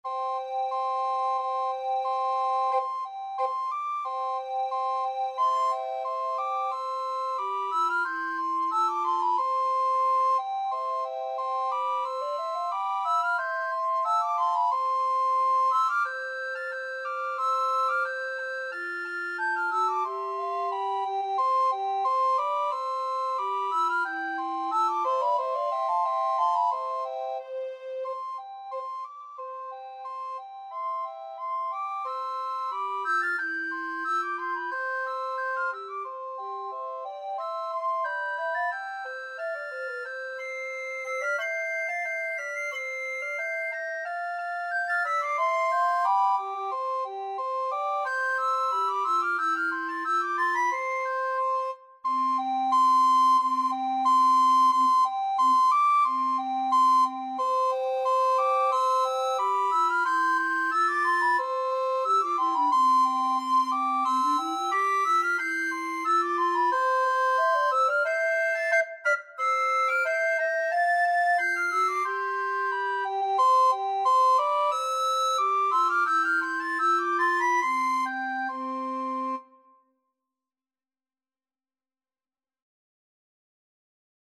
Soprano Recorder 1Soprano Recorder 2Tenor Recorder
Traditional Music of unknown author.
C major (Sounding Pitch) (View more C major Music for Recorder Trio )
2/2 (View more 2/2 Music)
March ( = c. 90)
Recorder Trio  (View more Intermediate Recorder Trio Music)